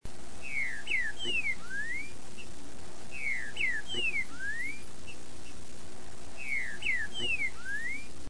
trinca-ferro, o mesmo que podemos ouvir na Thomaz Galhardo assim que a gente sai do trevo da BR em direção ao centro da cidade.
Todos sons do centro da cidade de Ubatuba.